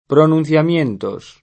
pronunTLamL%nto] (pl. pronunciamientos [